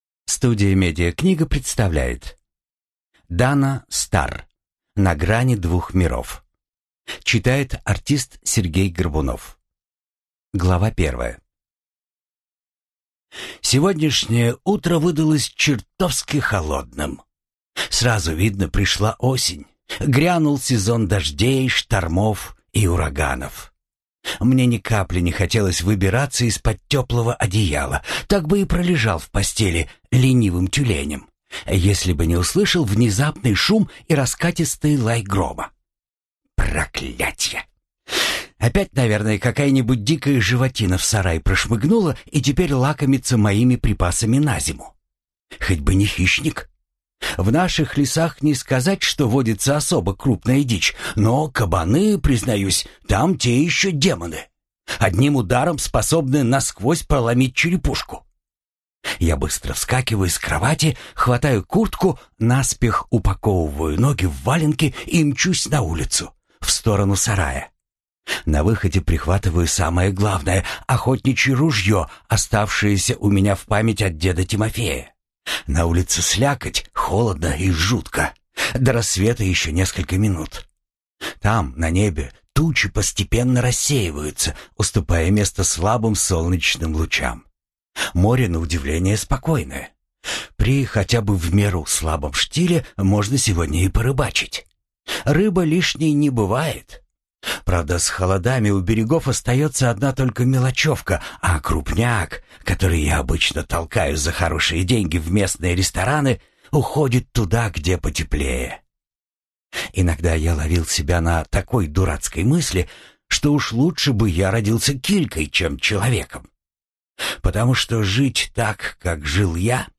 Аудиокнига На грани двух миров | Библиотека аудиокниг